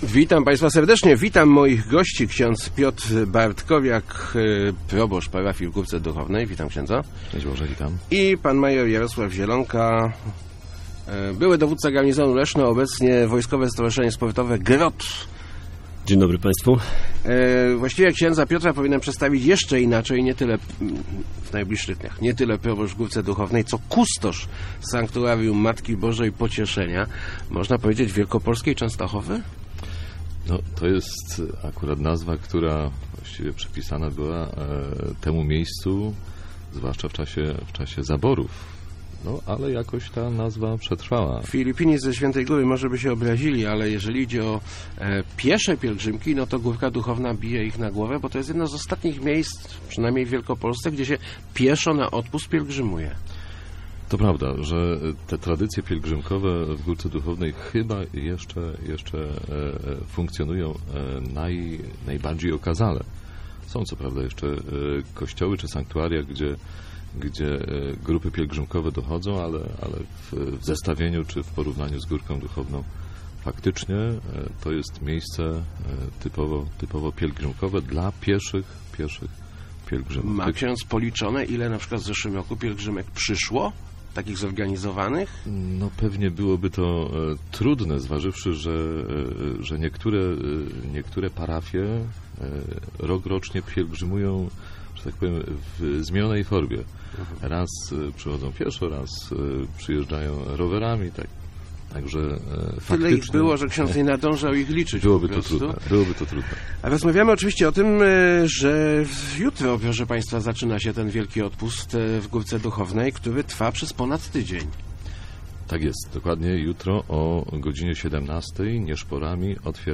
Start arrow Rozmowy Elki arrow Wielki odpust w Górce Duchownej